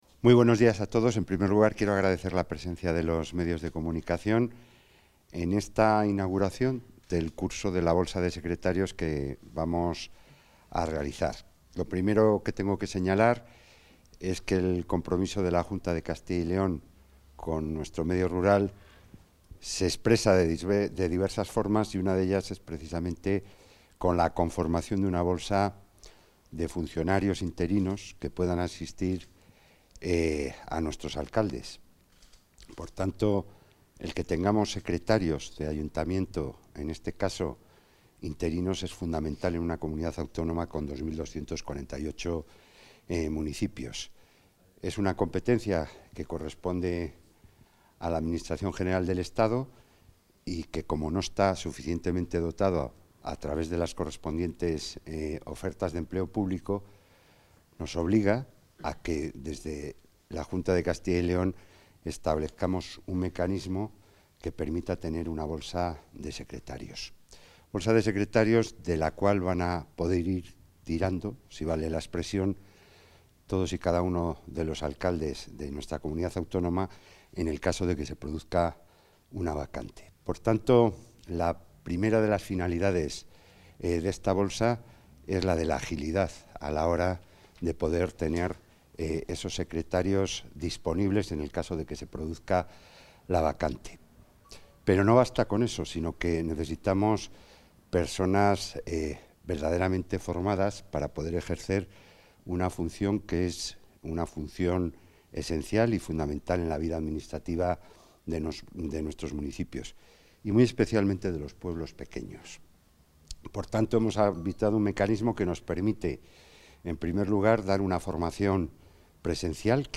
Intervención del consejero.